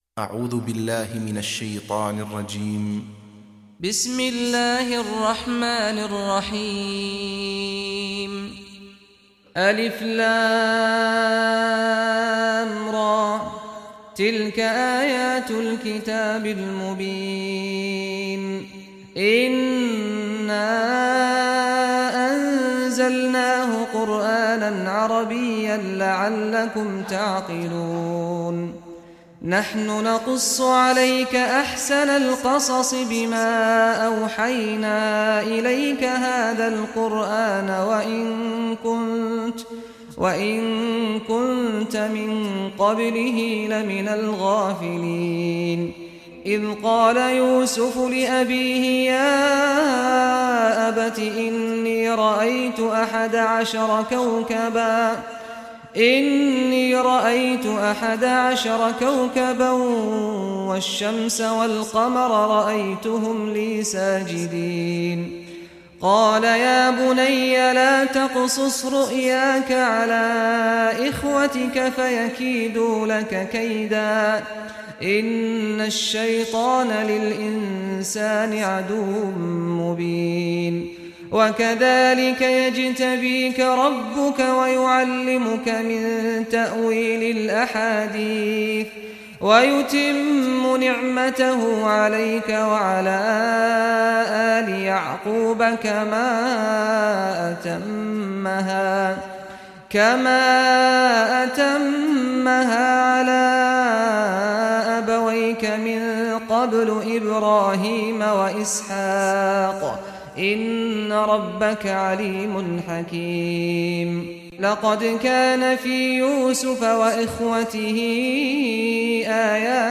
Surah Yusuf Recitation by Sheikh Saad Al Ghamdi
Surah Yusuf, listen or play online mp3 tilawat / recitation in Arabic in the beautiful voice of Sheikh Saad al Ghamdi.
12-surah-yusuf.mp3